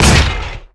acannonfire02.wav